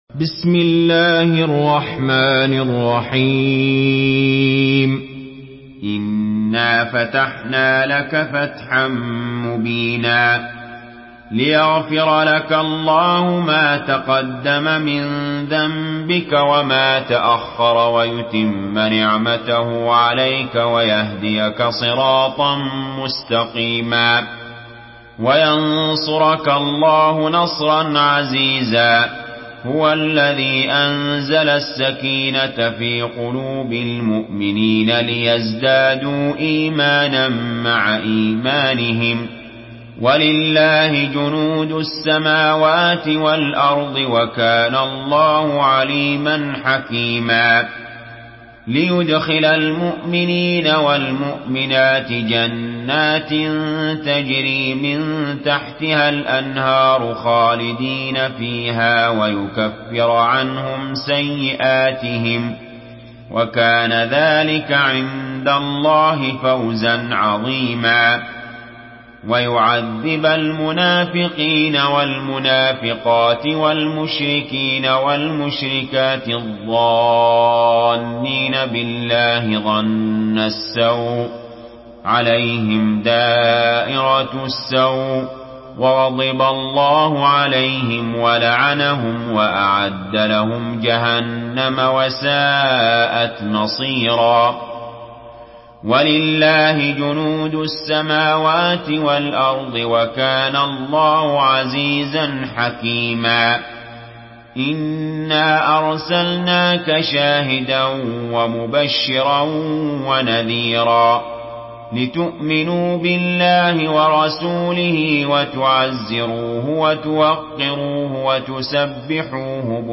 Murattal Hafs An Asim